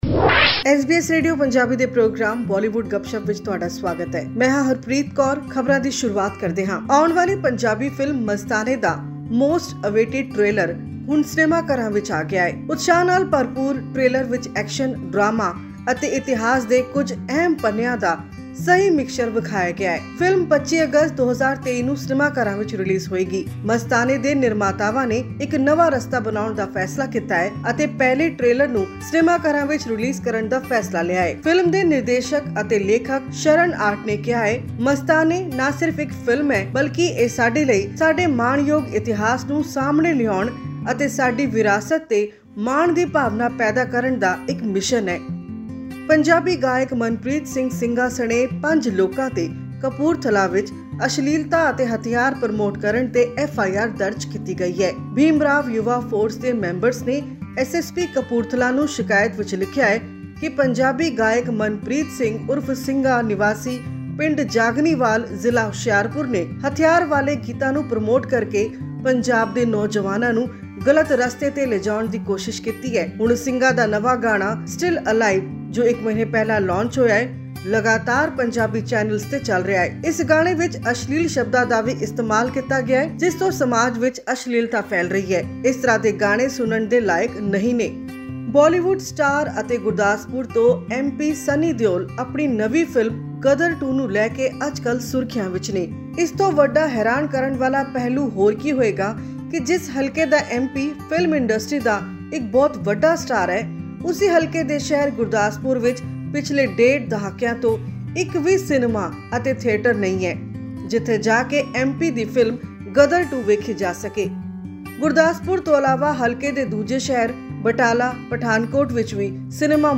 Mika Singh who was in the middle of his world tour has fallen ill and has postponed his much anticipated upcoming shows in Malaysia, Singapore, Australia and many other countries. This and much more in our latest news bulletin from Bollywood.